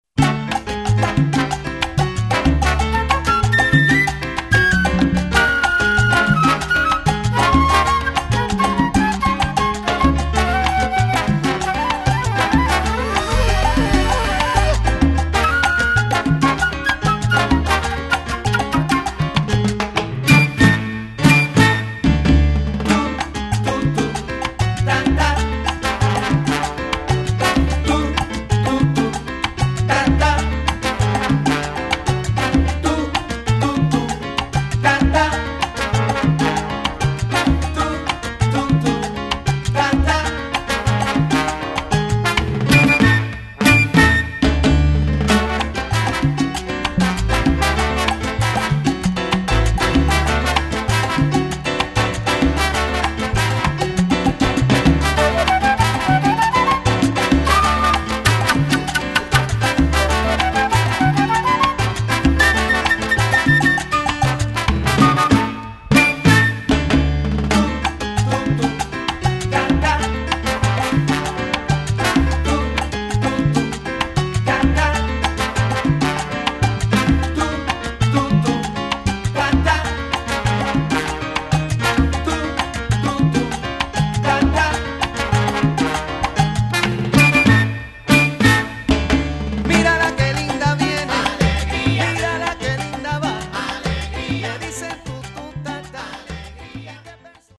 Latin jazz big band
Category: salsa
Style: mambo
Solos: vocal – short pregón with easy coro